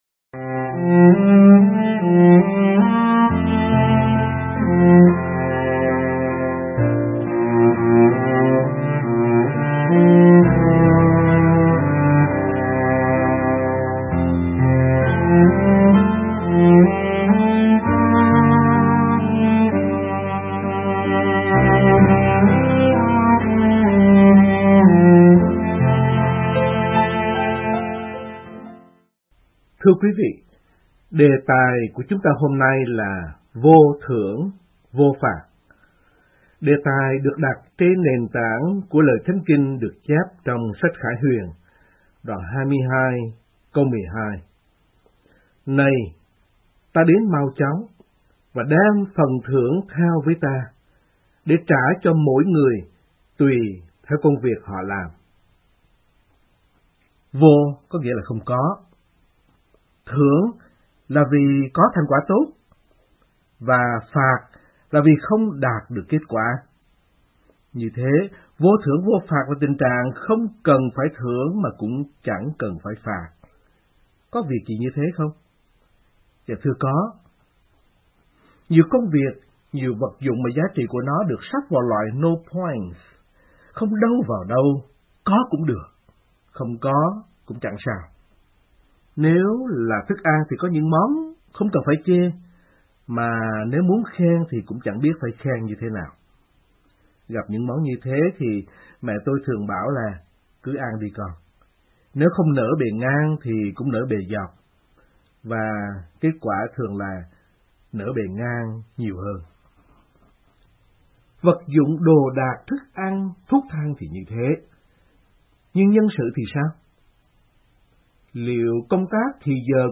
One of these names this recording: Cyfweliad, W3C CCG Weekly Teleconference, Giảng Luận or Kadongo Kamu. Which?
Giảng Luận